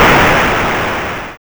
explosion_3.wav